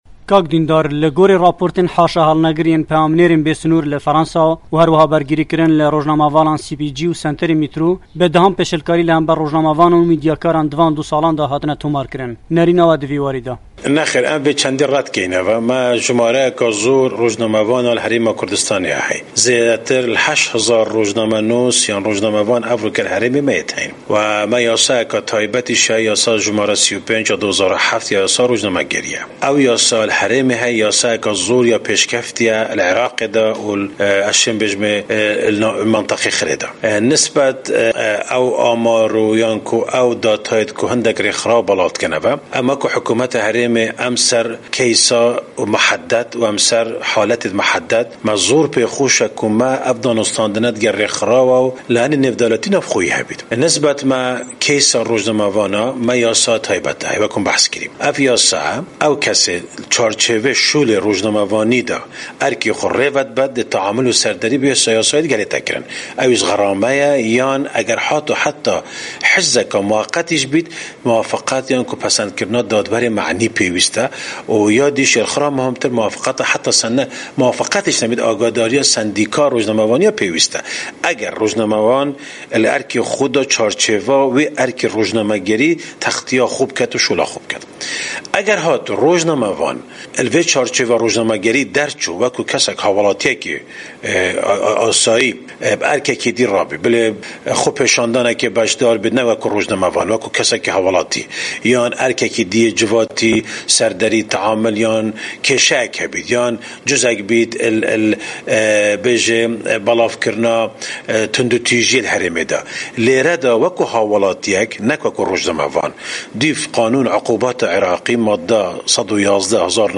وتووێژ لەگەڵ دیندار زێباری